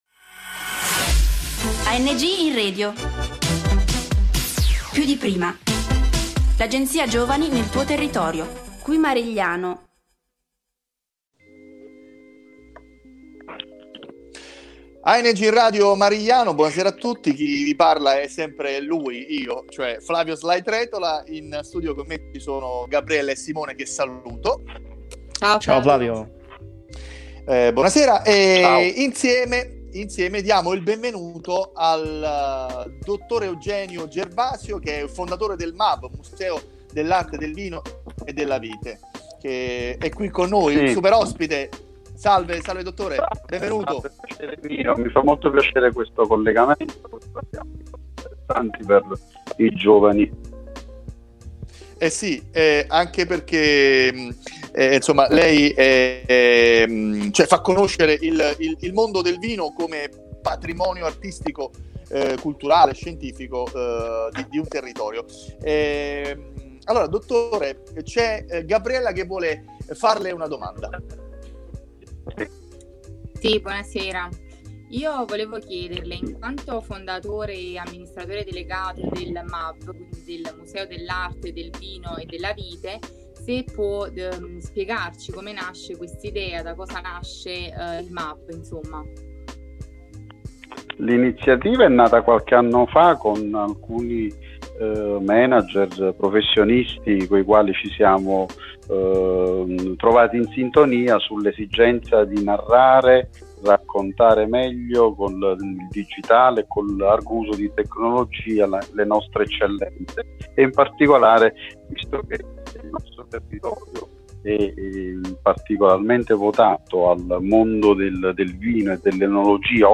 la trasmissione di buone pratiche ai più giovani: tutto questo nella bella chiacchierata